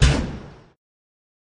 Safety Guard Close With Clunk, Computerized Mill